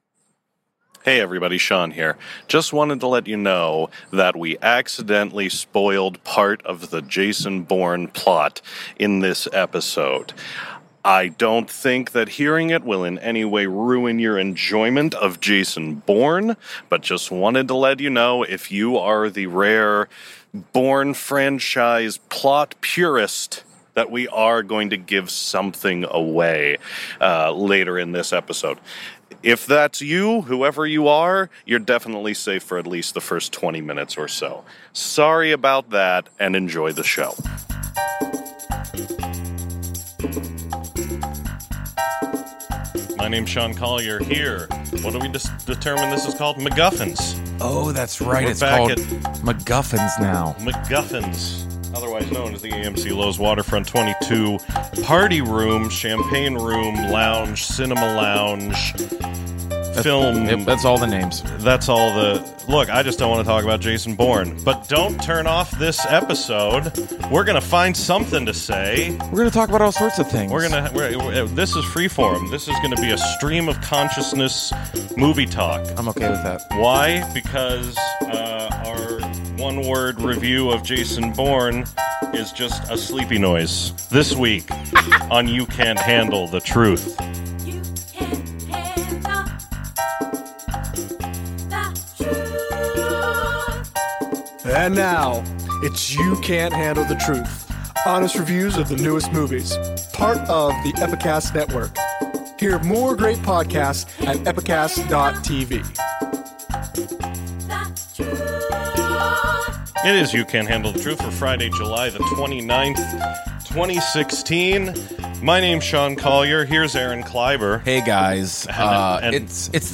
A stream-of-consciousness conversation winds into, around, and back to JASON BOURNE, the latest entry in the long-running action franchise.